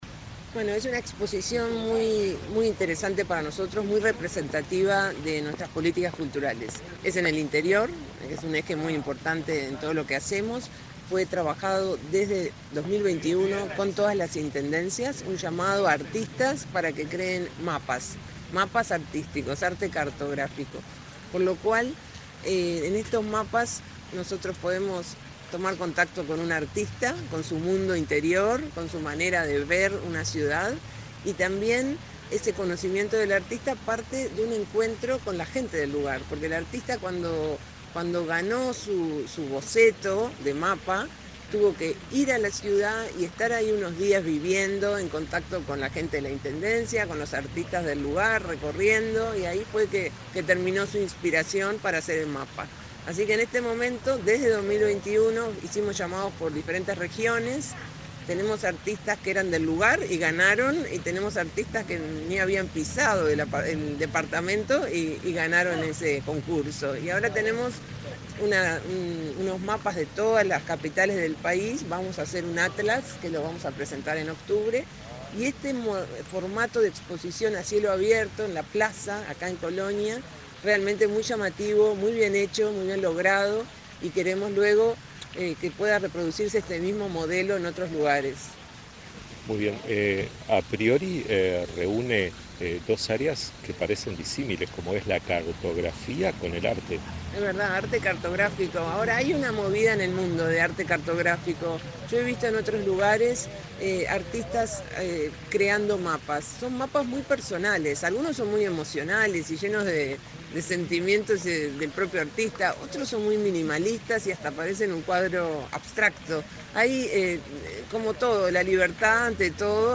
Entrevista a la directora nacional de Cultura, Mariana Wainstein
Entrevista a la directora nacional de Cultura, Mariana Wainstein 10/07/2024 Compartir Facebook X Copiar enlace WhatsApp LinkedIn La directora nacional de Cultura, Mariana Wainstein, dialogó con Comunicación Presidencial, durante su recorrida por la exposición a cielo abierto en fotogalería, que se presenta en la plaza 25 de Agosto de la capital coloniense.